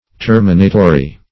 Terminatory \Ter"mi*na*to*ry\, a.